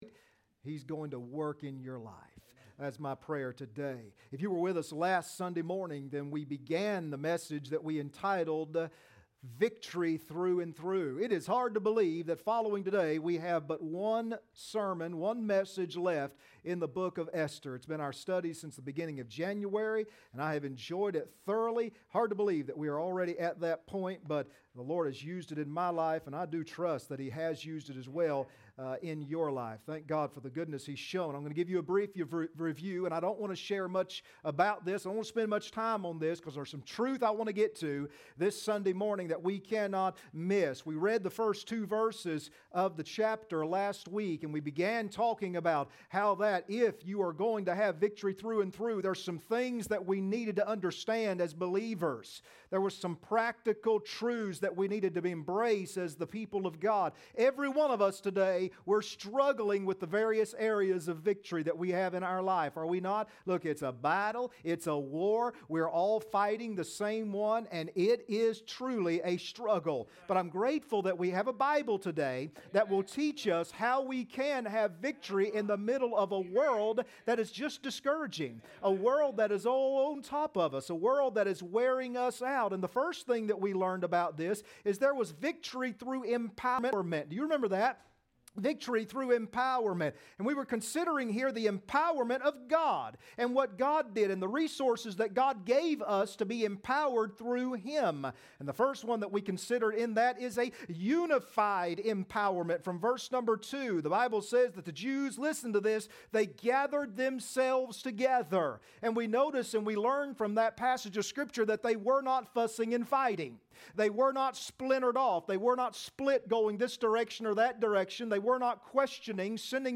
Esther 9:6-10 Service Type: Sunday Morning Next Sermon